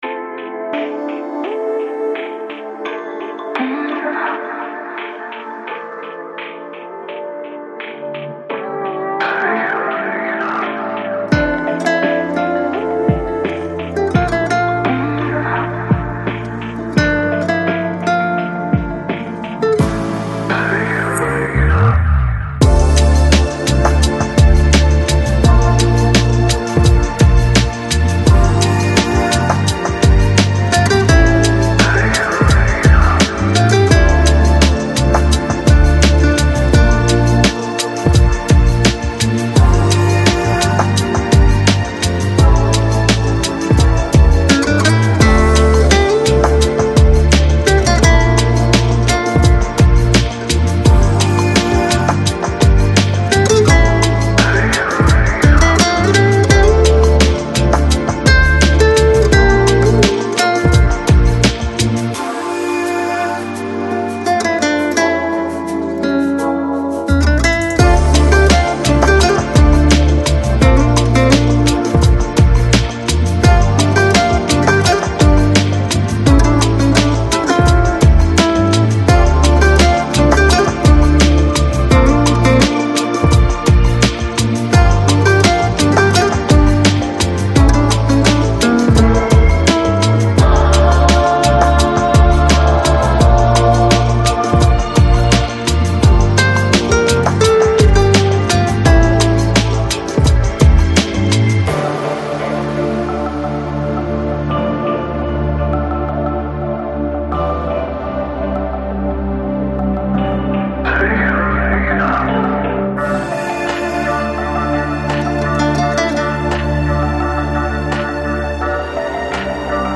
Жанр: Chill Out, Lounge, Downtempo